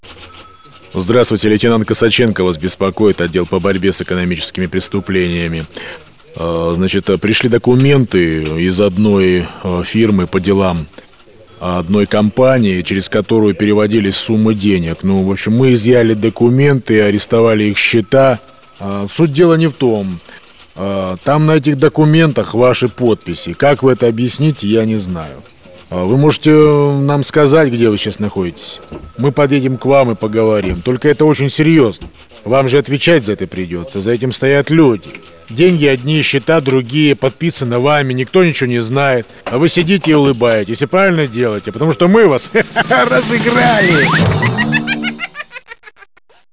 Очень громкий электронный звук